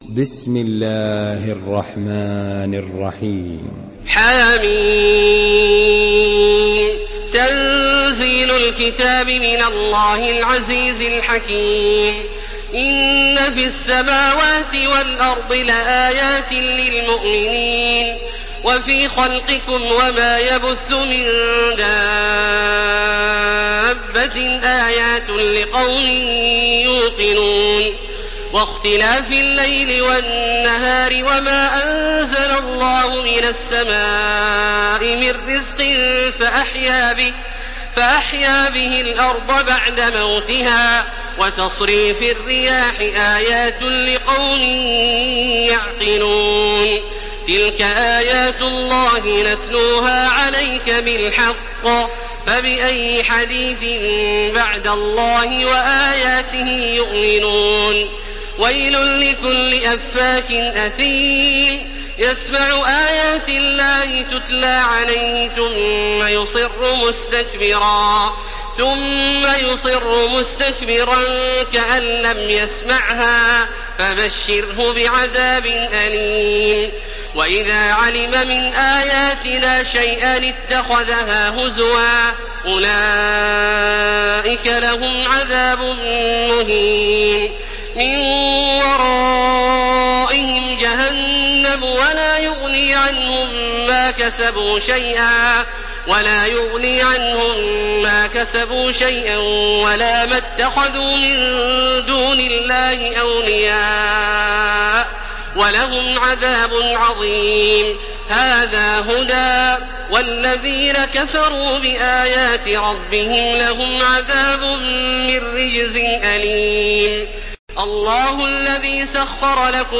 تحميل سورة الجاثية بصوت تراويح الحرم المكي 1431
مرتل